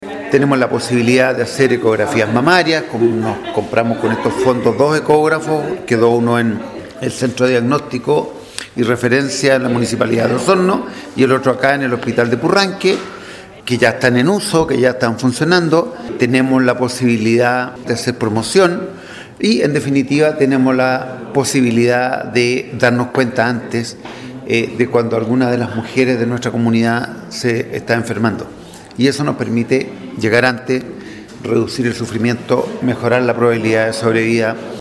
El director del Servicio de Salud Osorno, Rodrigo Alarcón, junto con agradecer el importante aporte del Gobierno Regional y del Consejo Regional, detalló que con este programa se podrán realizar ecografías mamarias, pues se adquirieron dos ecógrafos, uno quedó en el Centro de Diagnóstico y Referencia de Osorno, y el otro en el Hospital de Purranque.